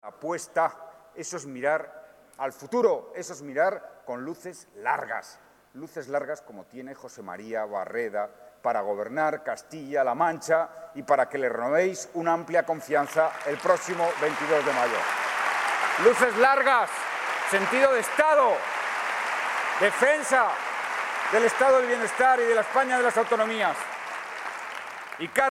En el acto han participado más de 2.000 personas, que llenaron dos salas del Palacio de Congresos y Exposiciones de Albacete, entre los que había militantes y simpatizantes del partido, pero también representantes de la sociedad, de los empresarios, los sindicatos, de los vecinos, de las asociaciones socio sanitarias, que recibieron un aplauso del público, por ser compañeros de viaje en el trabajo por Albacete y Castilla-La Mancha.